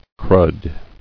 [crud]